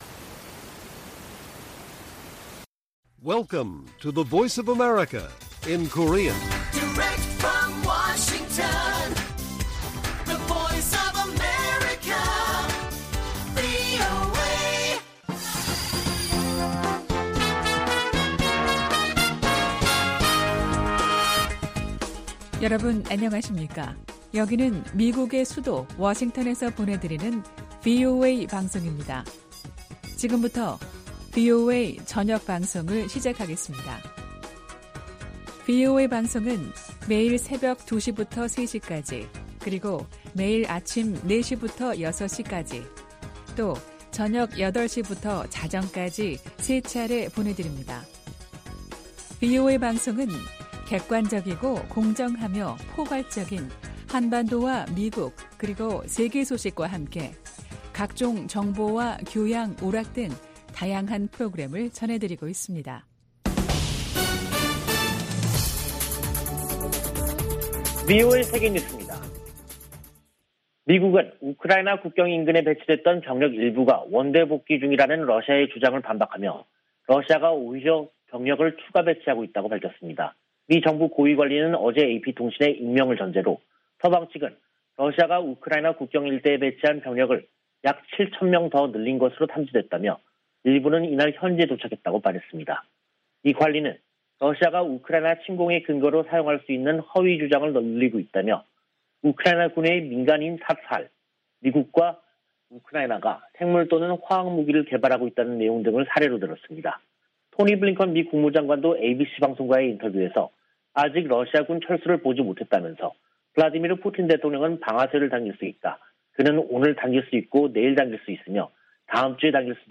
VOA 한국어 간판 뉴스 프로그램 '뉴스 투데이', 2022년 2월 17일 1부 방송입니다. 미국이 핵탄두 탑재 가능한 B-52H 전략폭격기 4대를 괌에 배치했습니다. 미국의 전문가들은 필요하다면 한국이 우크라이나 사태 관련 미국 주도 국제 대응에 동참해야한다는 견해를 제시하고 있습니다. 북한이 한 달 새 가장 많은 미사일 도발을 벌였지만 미국인들의 관심은 낮은 것으로 나타났습니다.